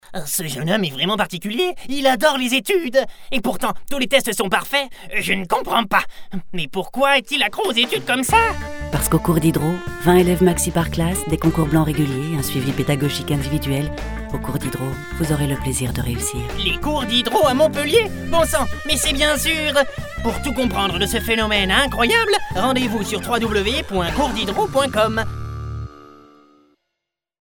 Composition de personnage
Scientifique loufoque – Ton excentrique et décrochages aigus
07-Personnage-loufoque-Voix-excentrique-avec-décrochages-aigües-028.mp3